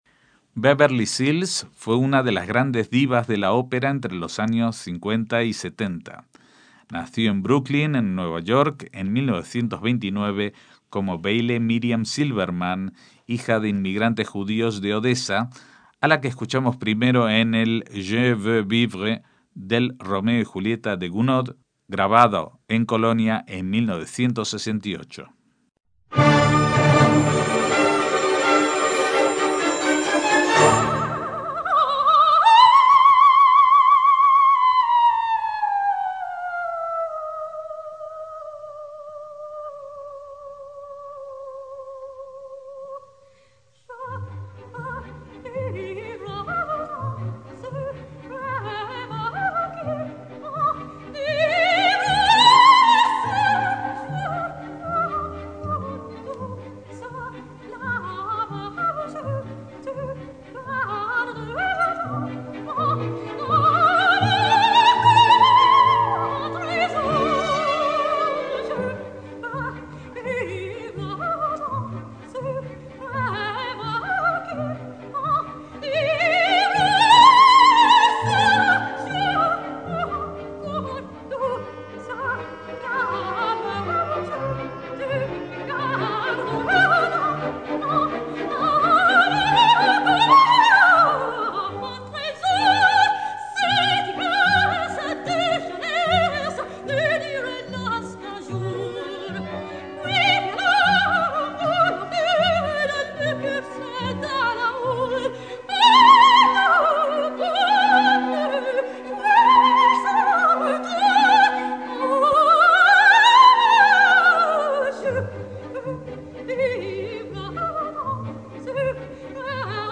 soprano de coloratura